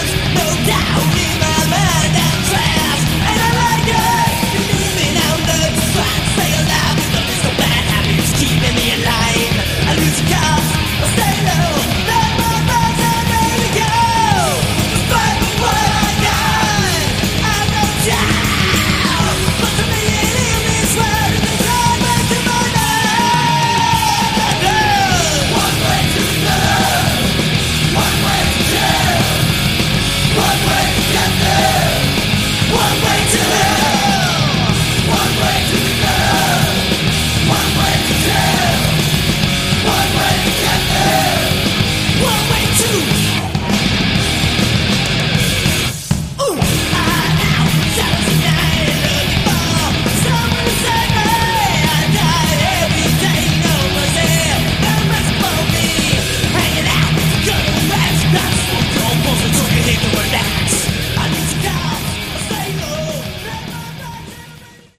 Category: Hard Rock
bass, backing vocals
guitars, keys, harp
lead and backing vocals